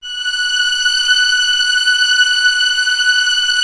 Index of /90_sSampleCDs/Roland L-CD702/VOL-1/STR_Vlns 7 Orch/STR_Vls7 f slo